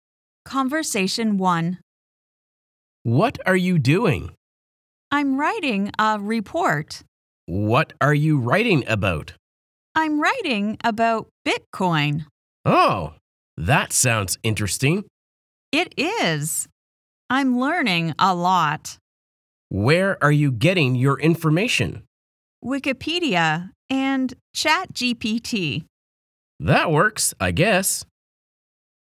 Kuuntelutehtävä: Kirjoita kuulemasi keskustelu vihkoon englanniksi. Mies ja nainen keskustelevat.